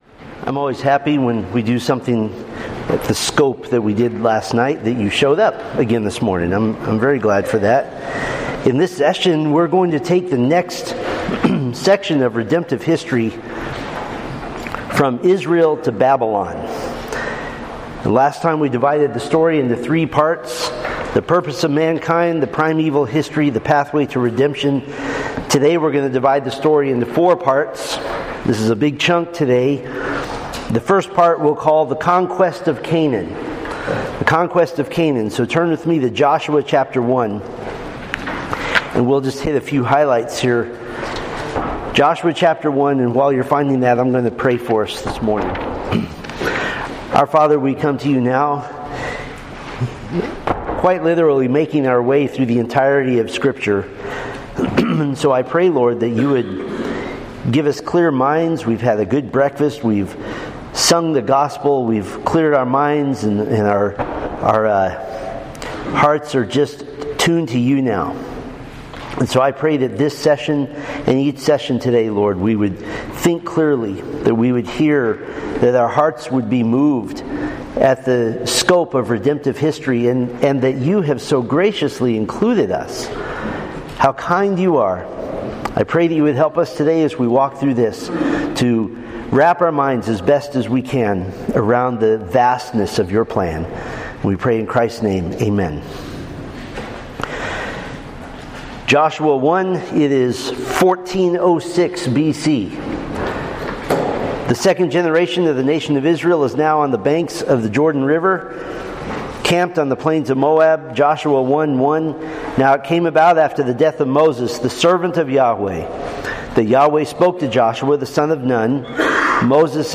Apple Podcasts Men's Retreat 2025 - Session 2 SPEAKER